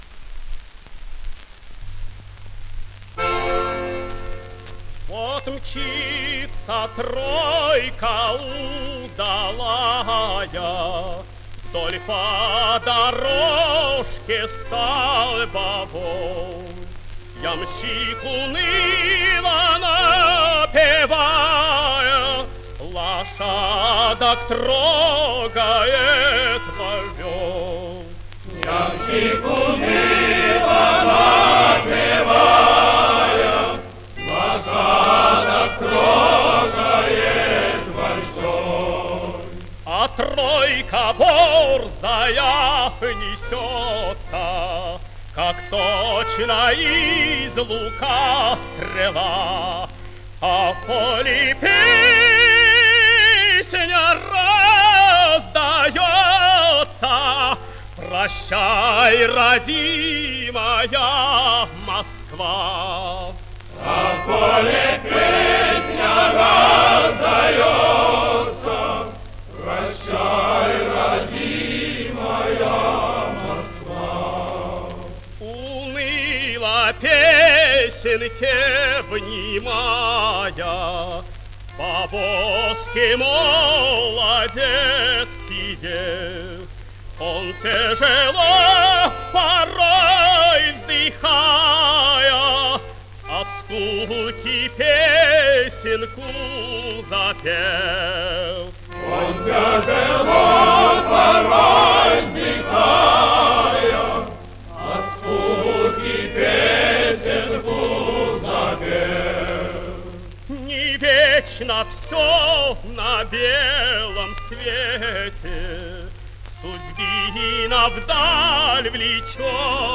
Обладал сильным лирико-драматическим тенором широкого диапазона, мягкого тембра с характерной окраской, драматическим и комедийным талантом.
Русская народная песня «Тройка».
Солист С. Н. Стрельцов.